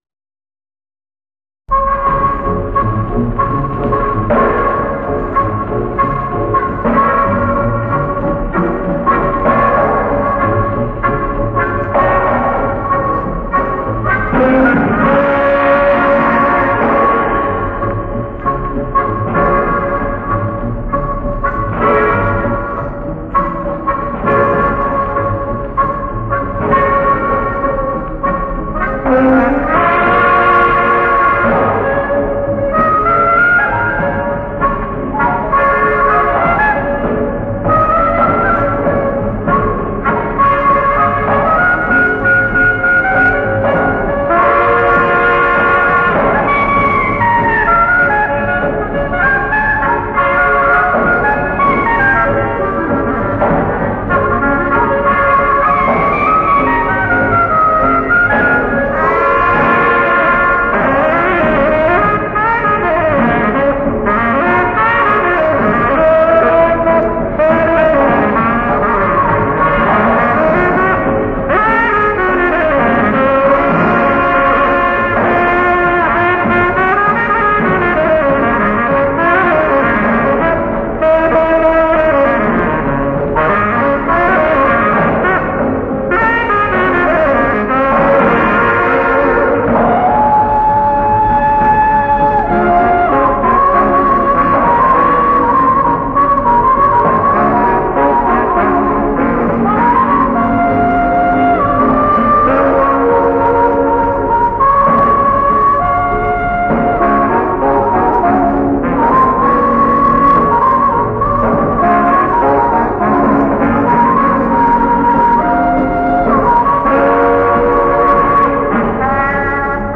Complesso Musicale Crazy Boys nell'anno 1952 durante un concerto al teatro Politeama Bisceglie.
Prove tecniche di registrazione presso il Politeama Italia di Bisceglie su filo d'acciaio (ditta Radio Valls - Bisceglie)